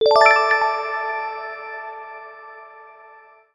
Category 🎮 Gaming
bounce game jump jumping trampoline sound effect free sound royalty free Gaming